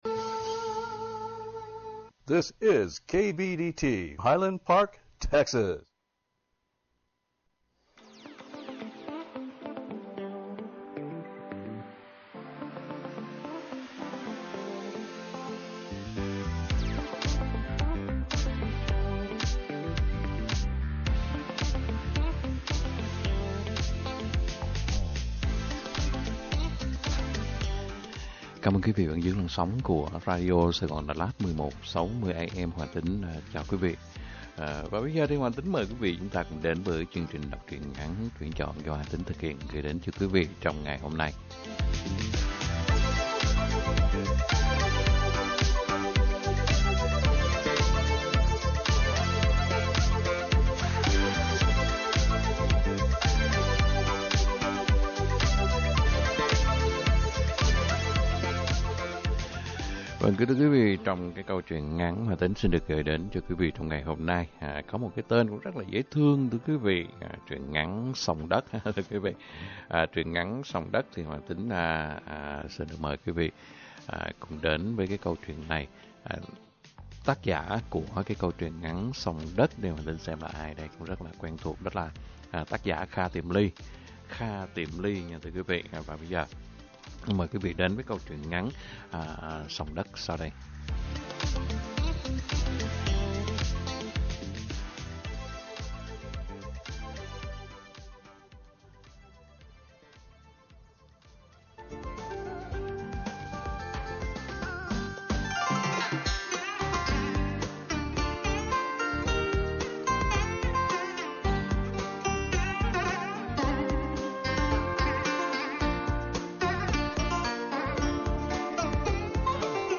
Đọc Truyện Ngắn = Xông Đất - 01/24/2023 . | Radio Saigon Dallas - KBDT 1160 AM